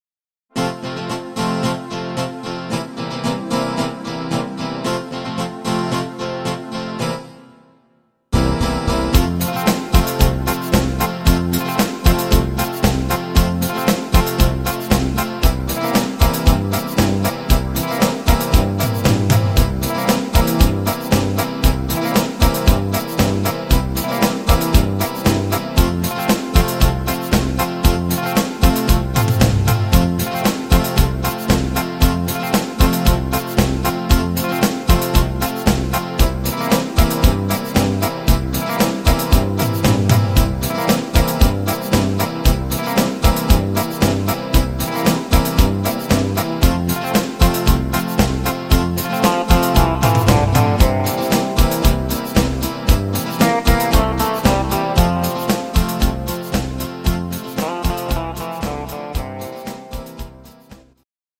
Instr. Saxophone